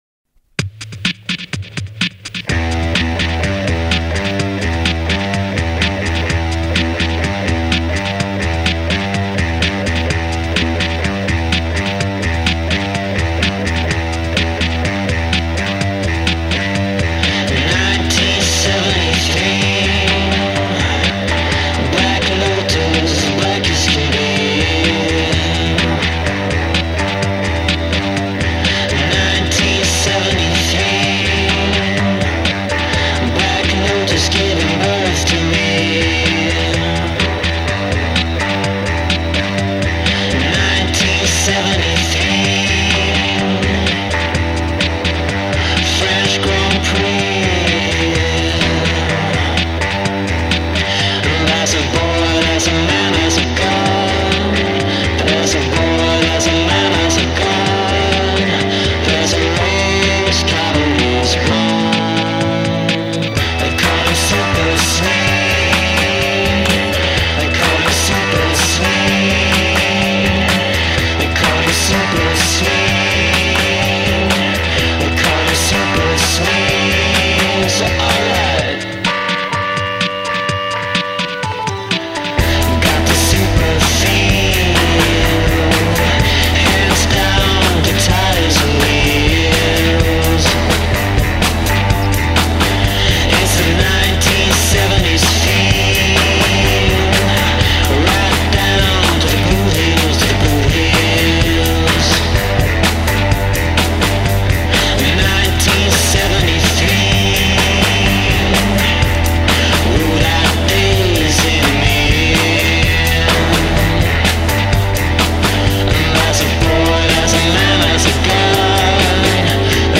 SUPERSWEDE (a rock´n roll tribute to ronnie peterson)